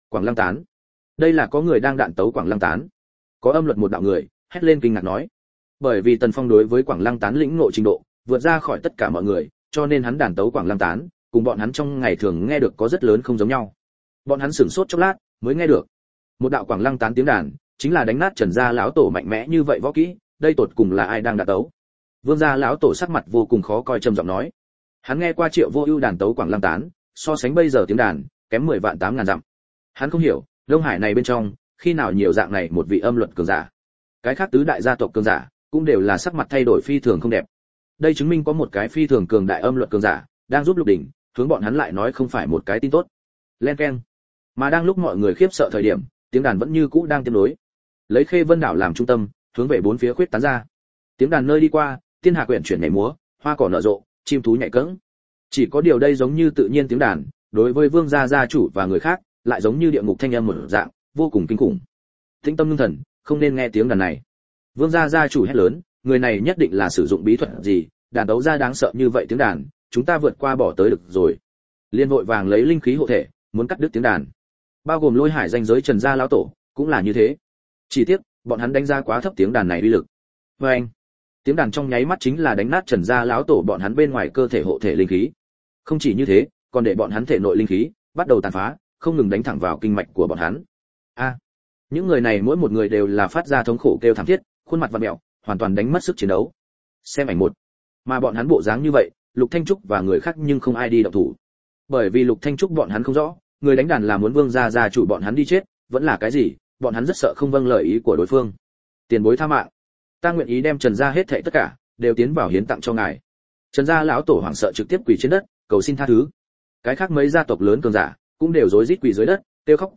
Ta, Bị Phế Thái Tử, Hoàng Lăng Đánh Dấu Ngàn Năm Audio - Nghe đọc Truyện Audio Online Hay Trên AUDIO TRUYỆN FULL